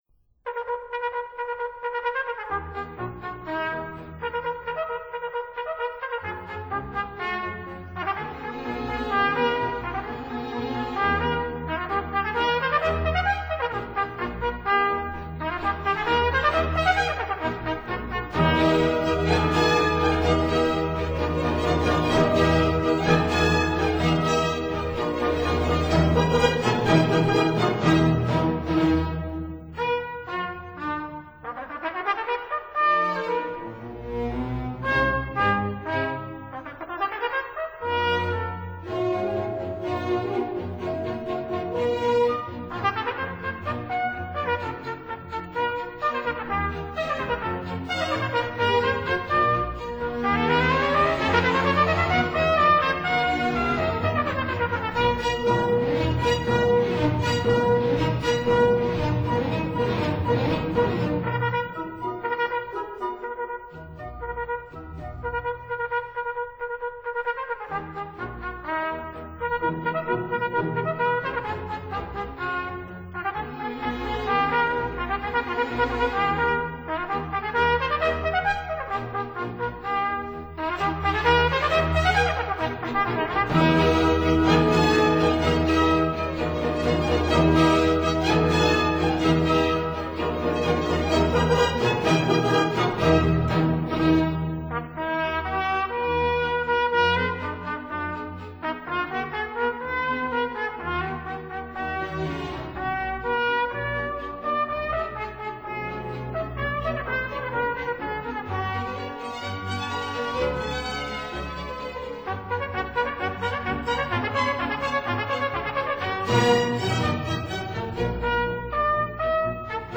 Natural & Keyed Trumpets
(Period Instruments)